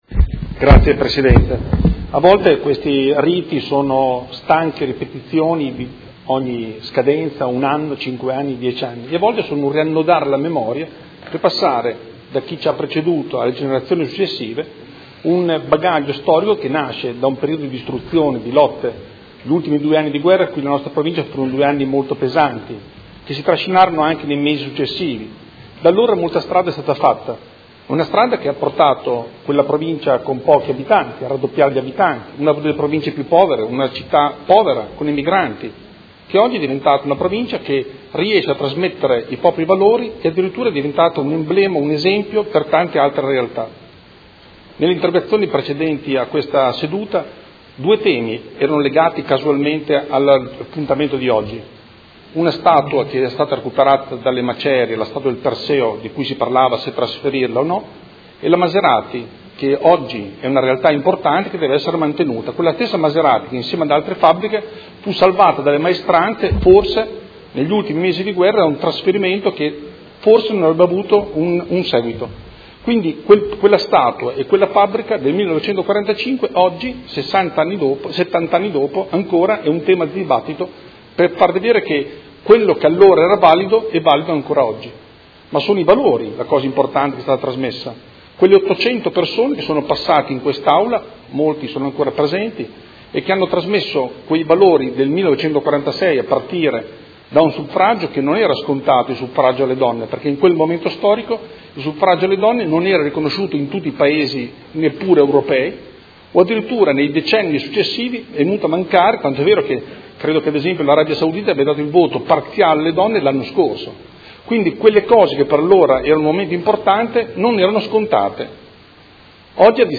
Seduta del 20/04/2016. Dibattito su Celebrazione del 70° dall'insediamento del primo Consiglio Comunale di Modena dopo il periodo fascista